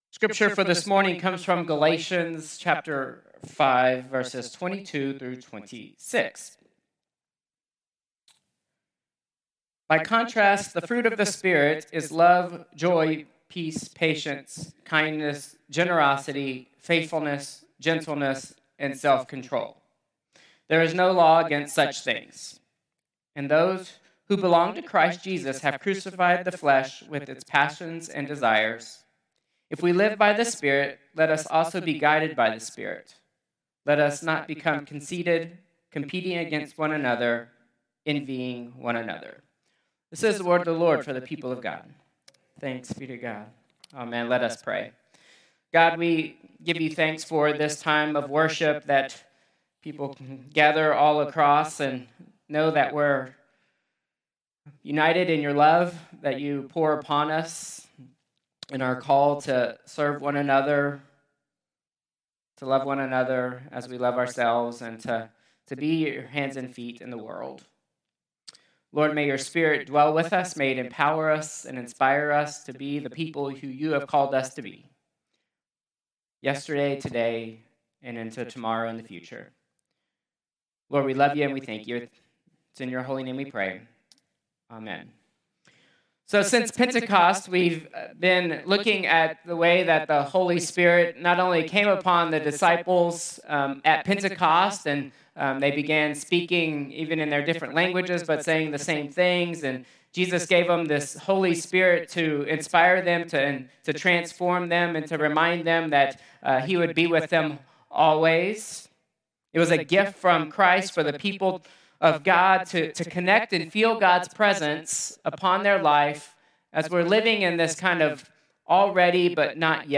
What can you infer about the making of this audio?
Contemporary Service 7/6/2025